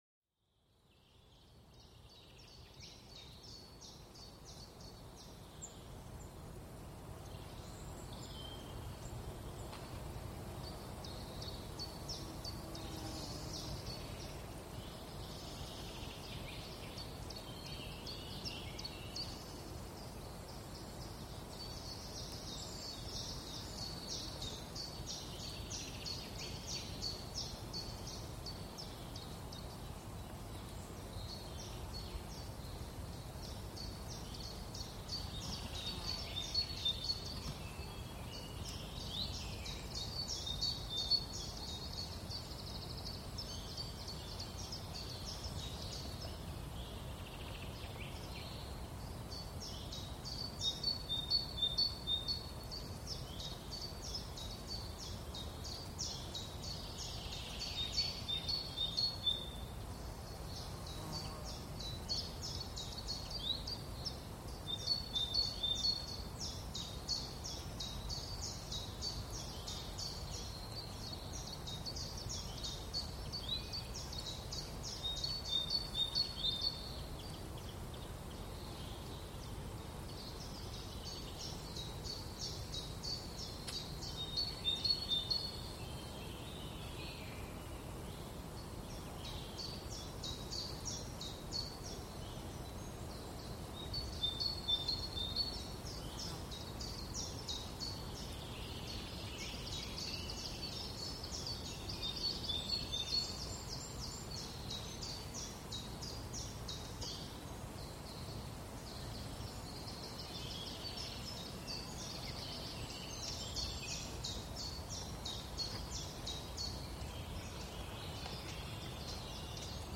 El sonido relajante de un bosque: relajación y bienestar
Descubre los sonidos tranquilos de un bosque, con el canto de los pájaros y el susurro de las hojas al viento. Déjate transportar por este paisaje sonoro que promueve la relajación y reduce el estrés.